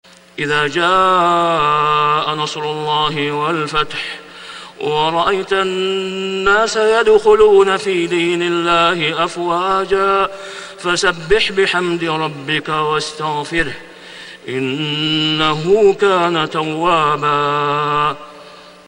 سورة النصر > السور المكتملة للشيخ أسامة خياط من الحرم المكي 🕋 > السور المكتملة 🕋 > المزيد - تلاوات الحرمين